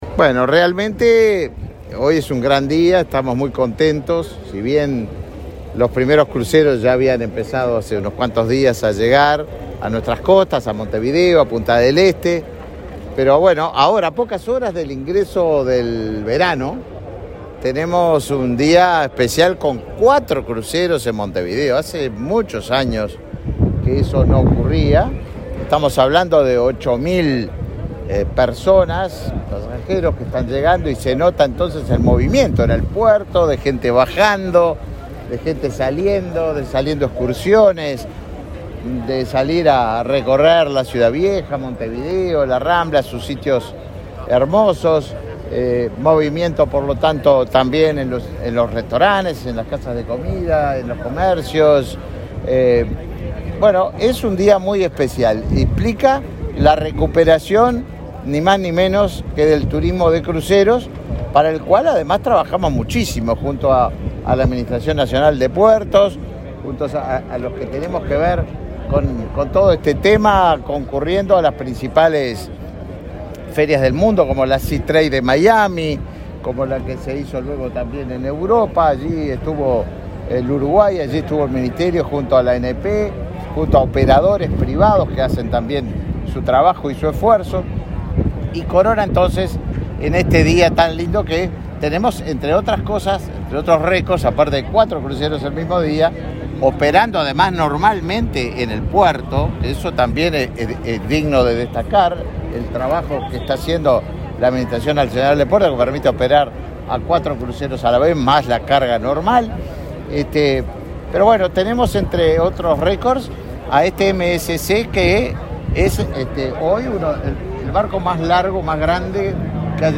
Declaraciones a la prensa del ministro de Turismo, Tabaré Viera
Declaraciones a la prensa del ministro de Turismo, Tabaré Viera 22/12/2022 Compartir Facebook X Copiar enlace WhatsApp LinkedIn Este jueves 22, autoridades del Ministerio de Turismo y la Administración Nacional de Puertos (ANP) lanzaron la temporada de cruceros y recibieron cuatro buques en el puerto de Montevideo. Antes, el ministro Tabaré Viera dialogó con la prensa.